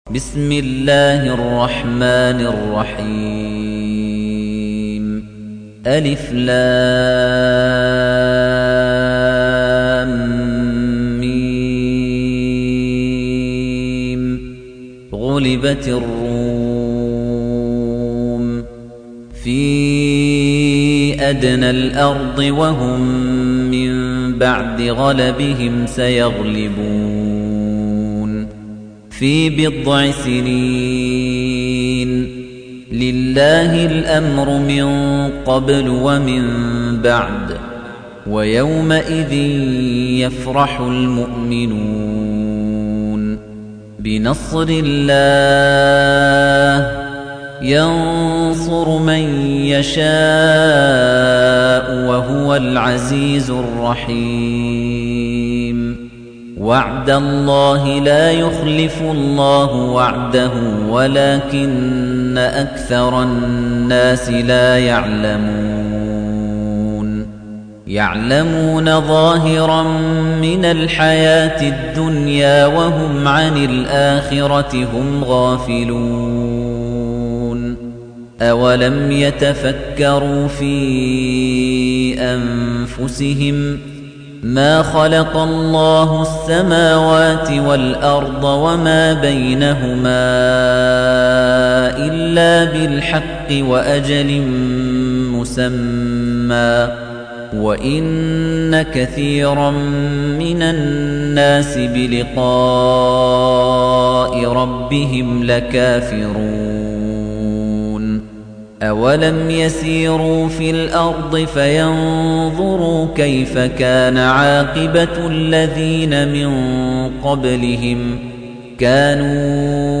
تحميل : 30. سورة الروم / القارئ خليفة الطنيجي / القرآن الكريم / موقع يا حسين